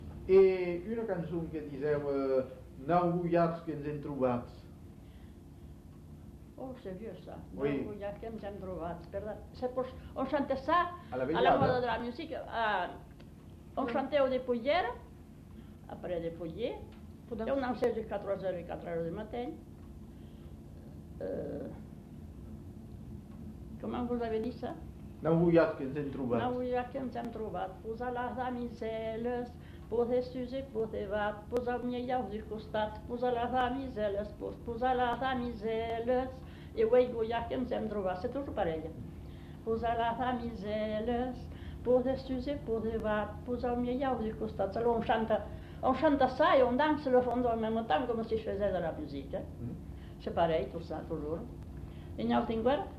Aire culturelle : Marsan
Genre : chant
Effectif : 1
Type de voix : voix de femme
Production du son : chanté
Danse : rondeau